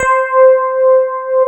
Index of /90_sSampleCDs/USB Soundscan vol.09 - Keyboards Old School [AKAI] 1CD/Partition A/13-FM ELP 1